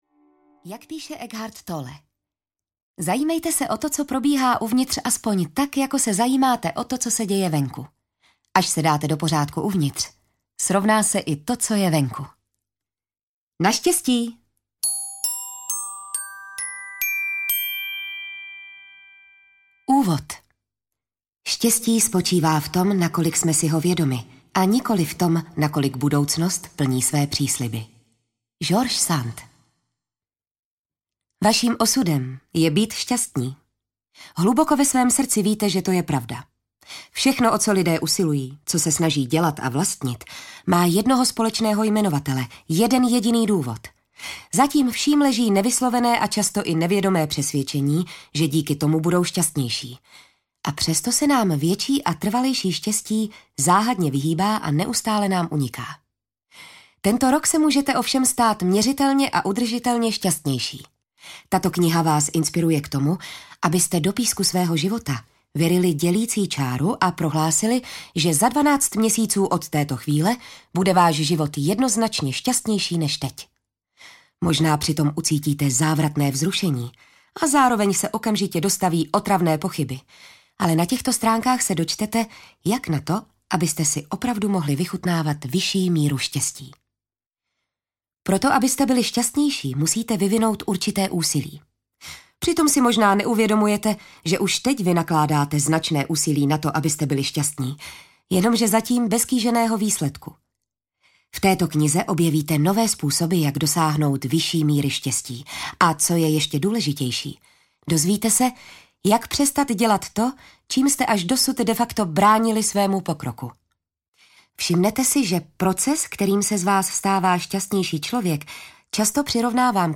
Šťastný život audiokniha
Ukázka z knihy